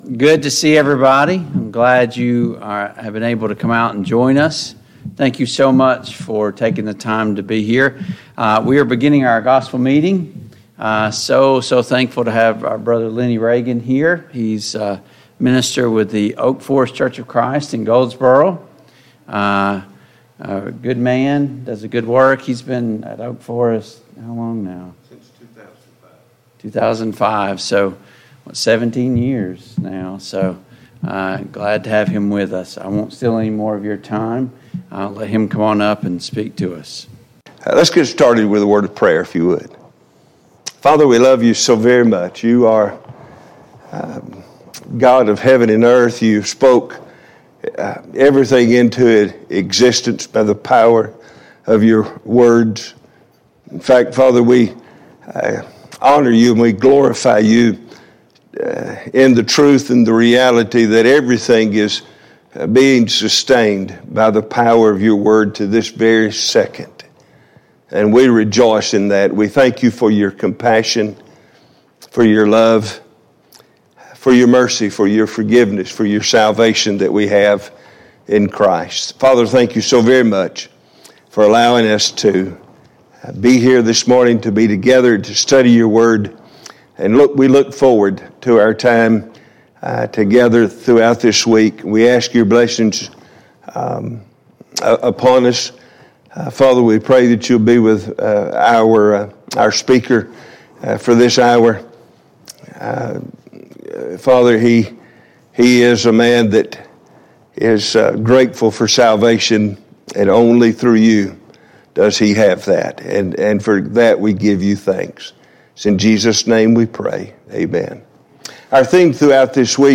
Passage: Psalm 57 Service Type: Gospel Meeting Download Files Notes « 49.